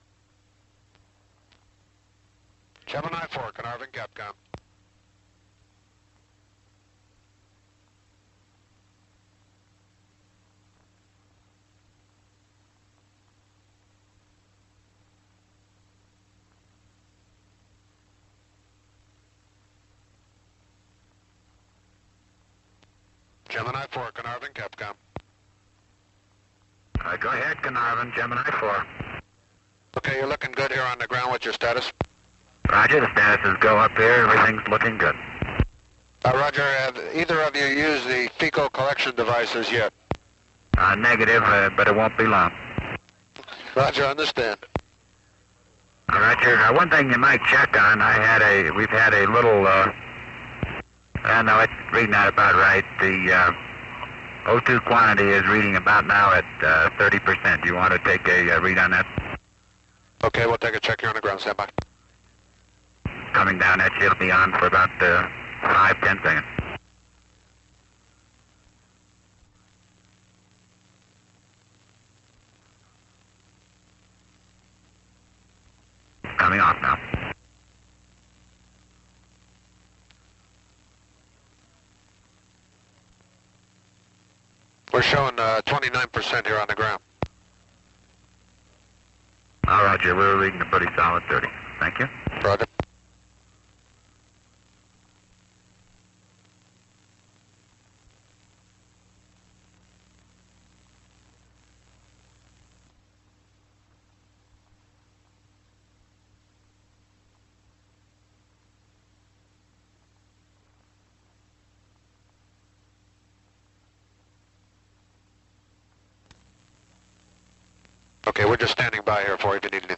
Gemini IV audio as recorded at Carnarvon
The audio files of each pass are unaltered, however periods of silence between passes have been removed or reduced.